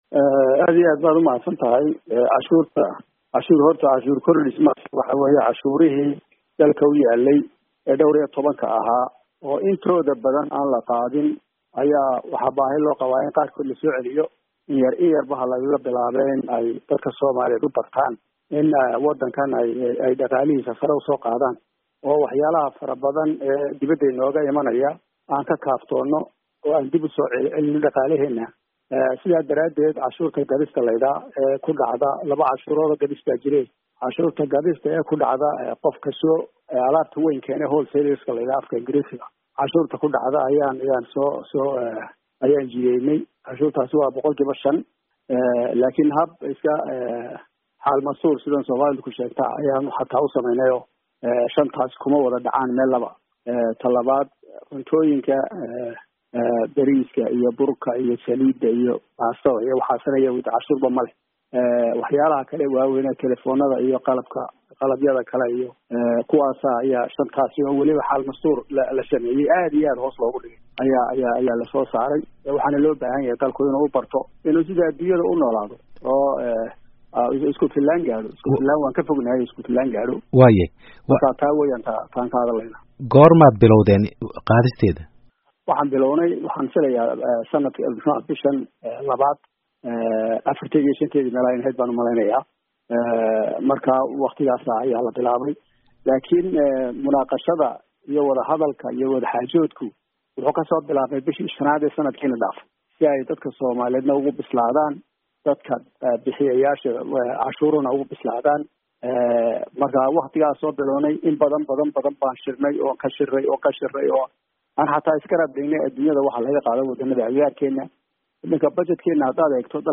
Dhagayso: Wareysi dheer oo uu Wasiirka Maaliyadda DFS ku sharxayo Cantuurta ay dawladu Qaadayso
20 Feb 2018 (Puntlandes) Wasiirka maaliyadda ee Soomaaliya Dr Cabdiraxmaan Ducaale Beyle oo waraysi dheer oo aad xasaasi u ah siiyey idaacadda VOA ayaa sheegay in qaadista canshuurta iibka ee alaabooyinka jumlada ah ay muhiim u tahay shaqada dawladda iyo dhismaha kalsoonida lagu qabo dawladda.